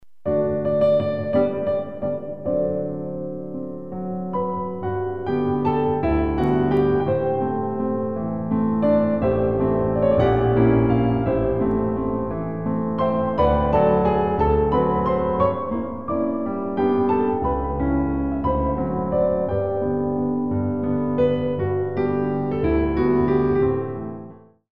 Valse